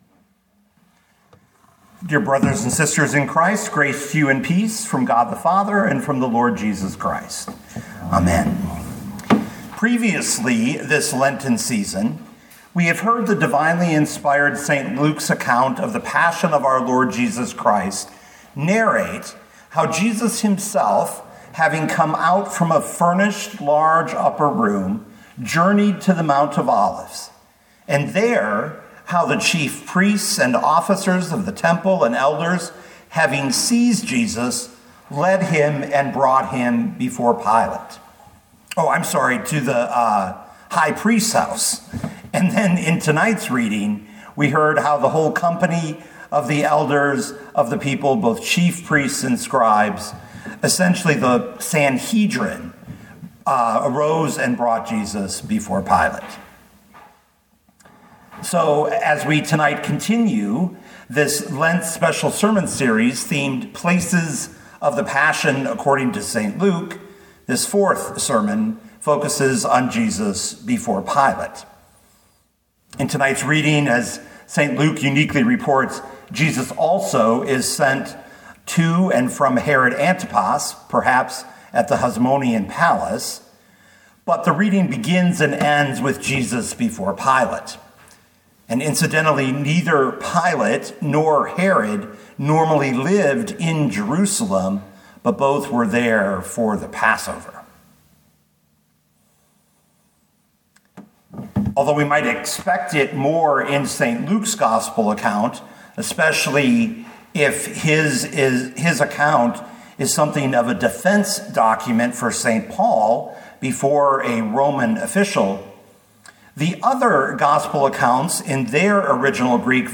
2025 Luke 23:1-25 Listen to the sermon with the player below, or, download the audio.